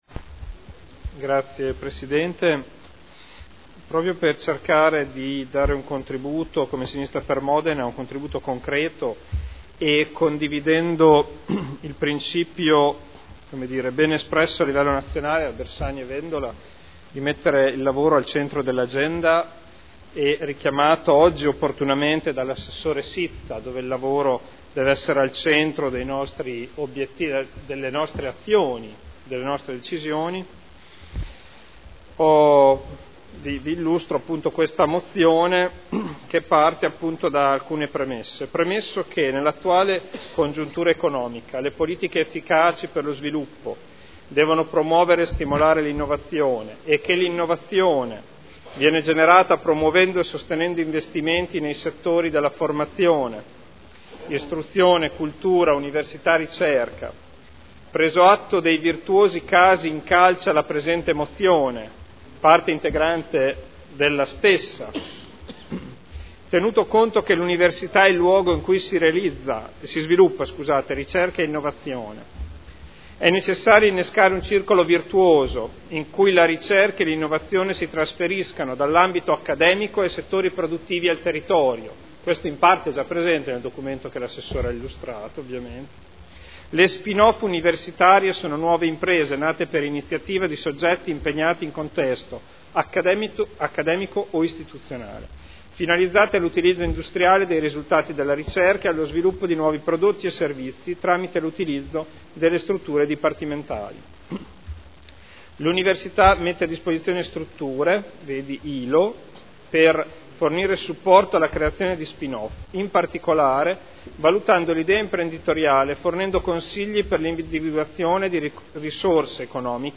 Federico Ricci — Sito Audio Consiglio Comunale
Seduta del 28/01/2013. Presenta mozione - 11982